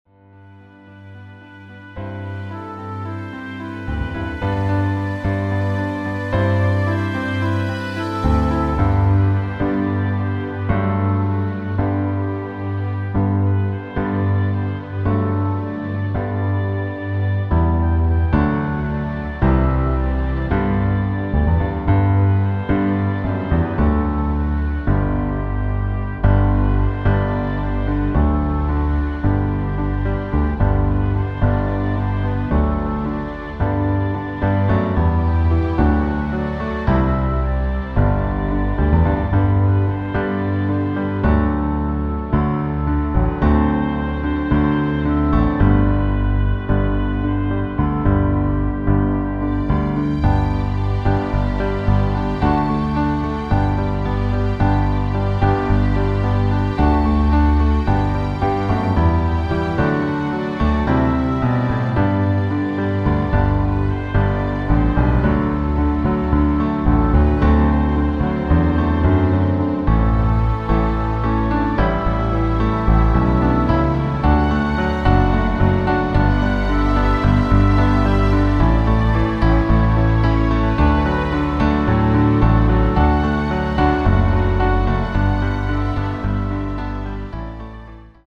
• Tonart: Bb Dur, G Dur (Originaltonart)
• Art: Klavierversion mit Streichern
• Das Instrumental beinhaltet NICHT die Leadstimme